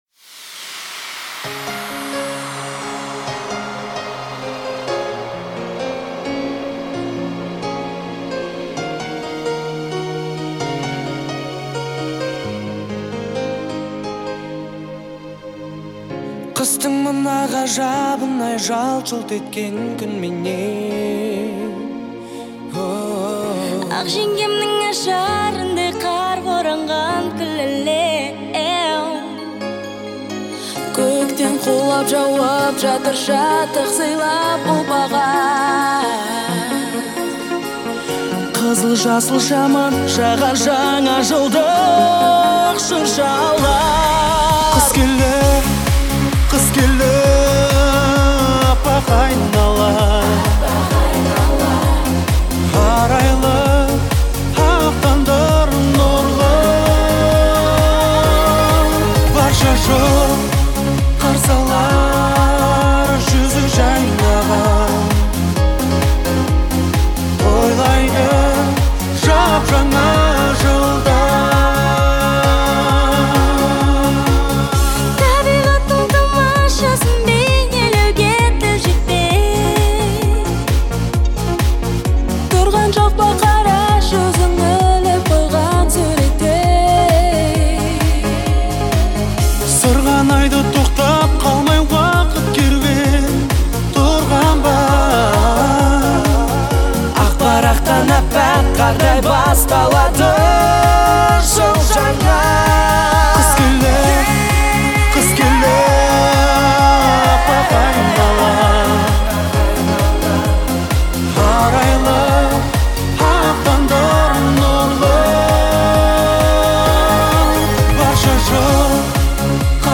это яркое и мелодичное произведение в жанре а cappella